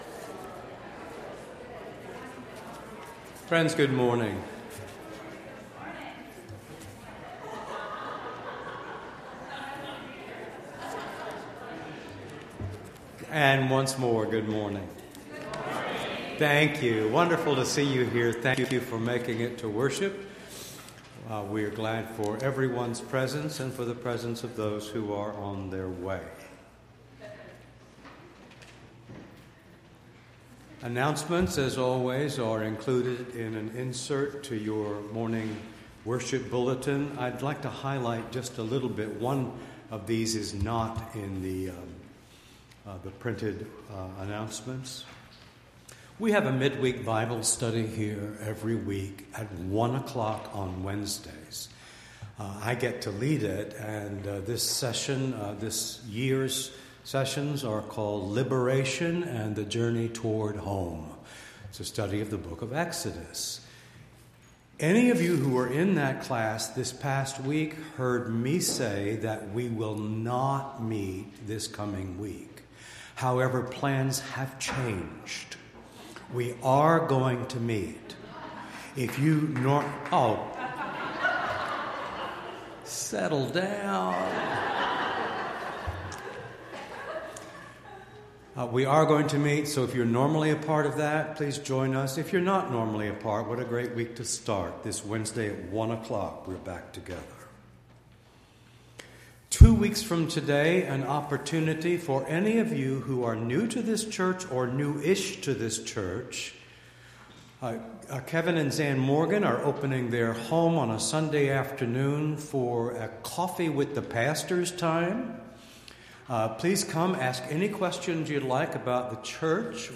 Entire February 16th Service